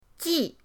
ji4.mp3